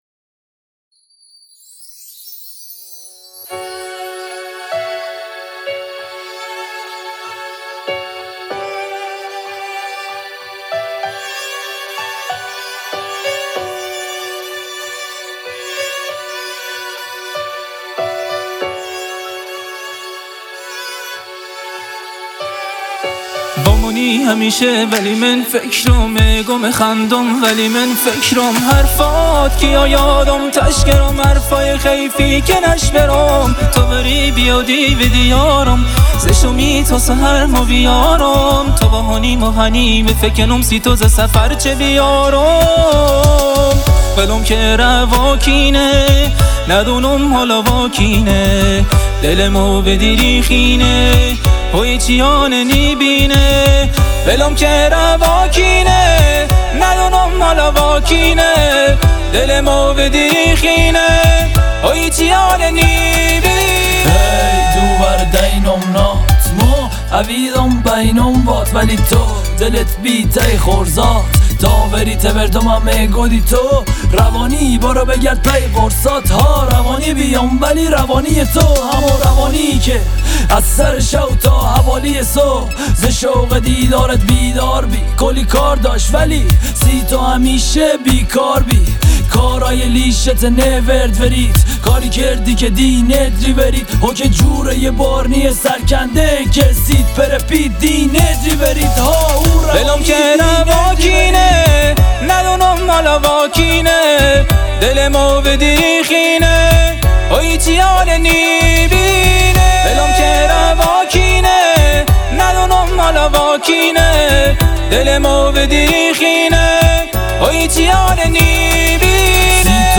موزیک موزیک نواحی